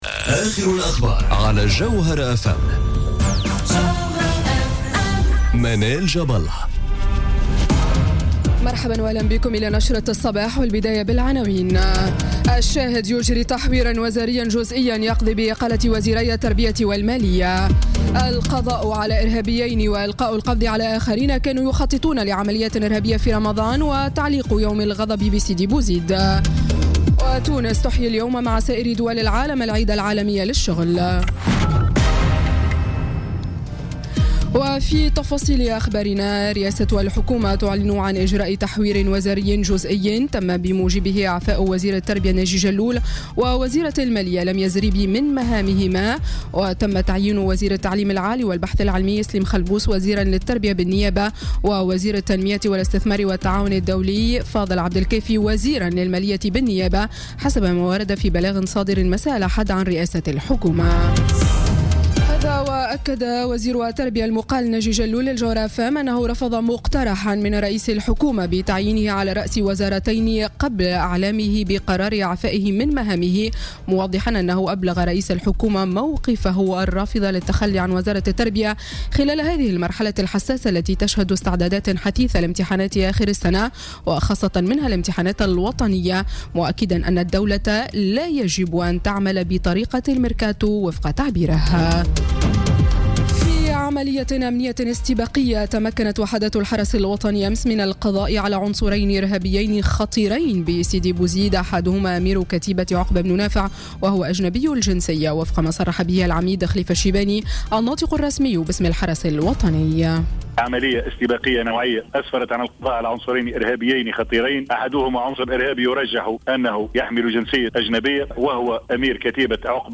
نشرة أخبار السابعة صباحا ليوم الإثنين غرة ماي 2017